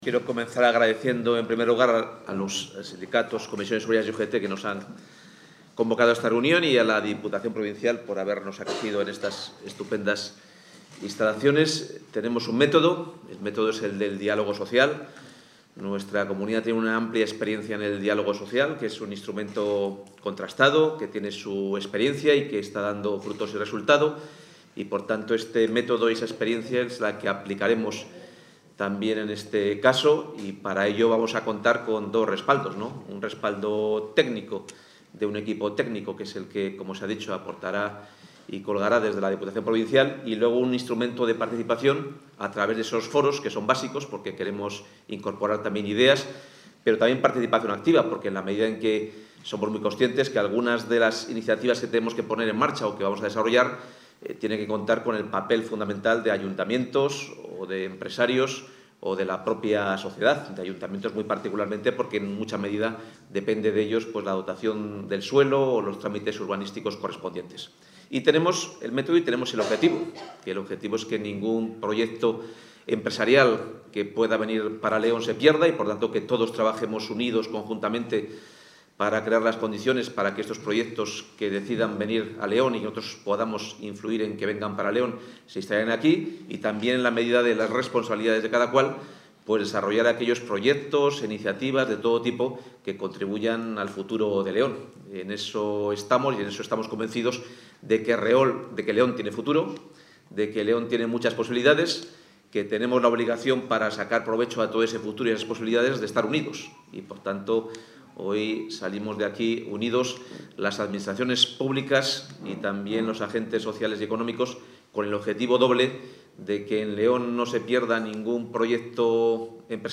Material audiovisual sobre la participación del consejero de Economía y Hacienda en la reunión para constituir la Mesa por el futuro de la provincia de León
Intervención del consejero.